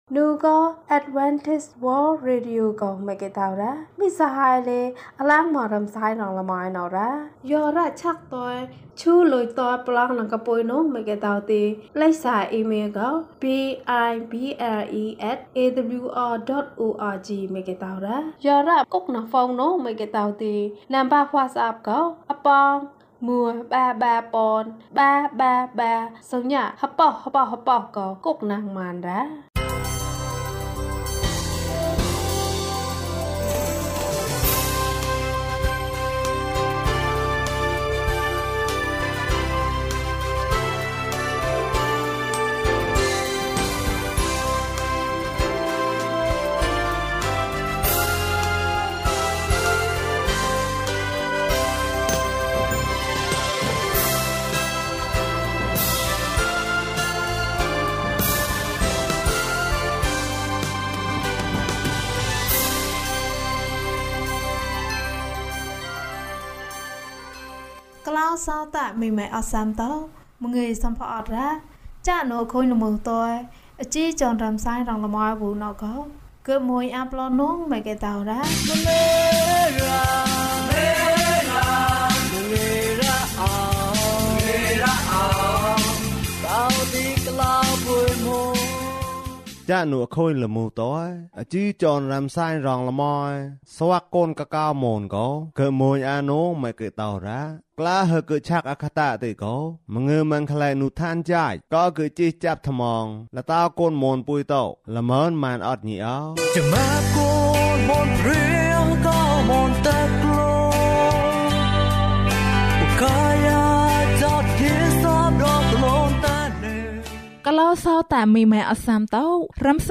ငါဘုရားသခင့်စကားများကိုမျှဝေမည်။၀၁ ကျန်းမာခြင်းအကြောင်းအရာ။ ဓမ္မသီချင်း။ တရားဒေသနာ။